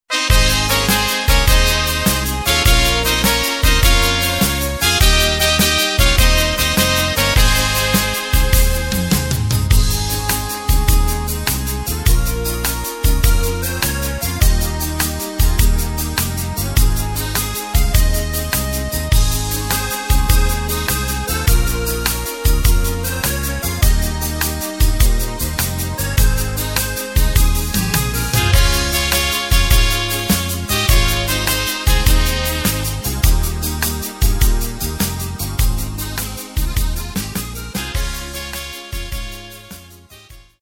Takt:          4/4
Tempo:         102.00
Tonart:            Ab
Schlager aus dem Jahr 1991!
Playback mp3 Demo